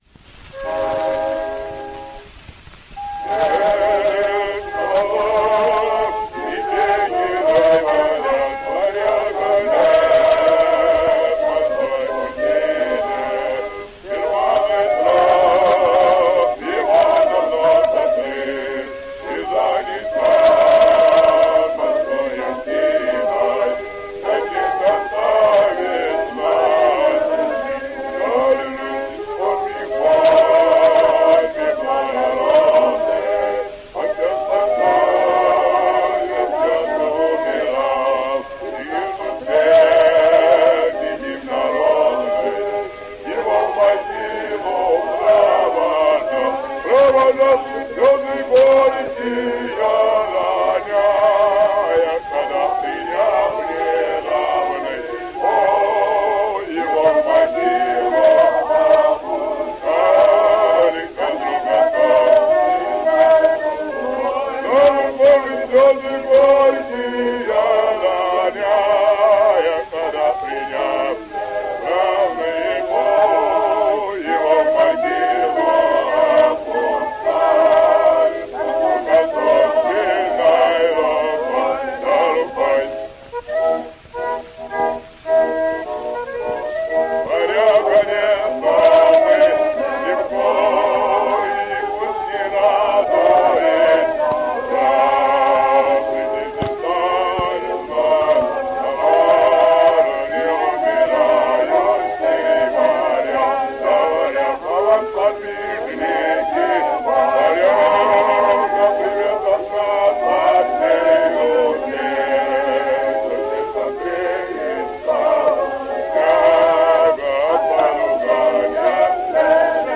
Исполняет «Марш Варяга» (или же «Варяга нет» — как хотите) русский народный хор под управлением А. А. Александрова.
Песня-марш на слова «Варяга нет» (грамзапись Extraphone 23889)